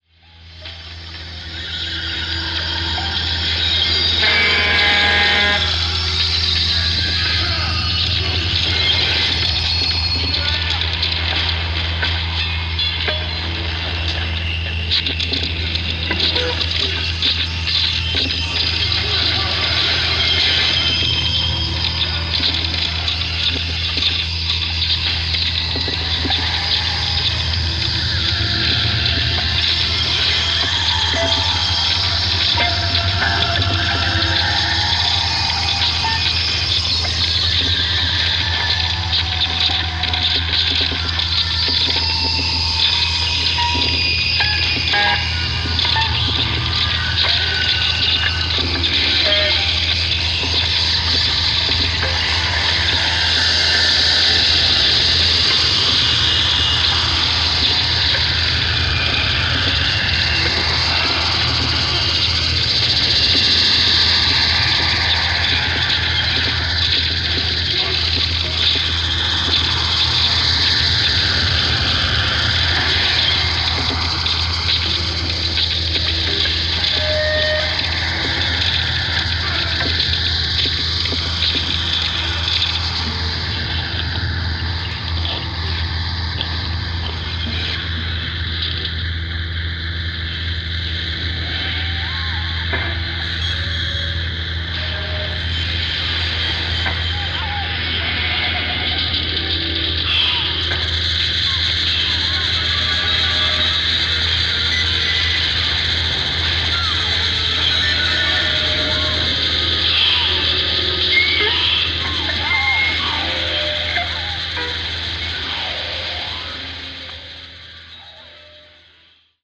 Melting cinematic sounds.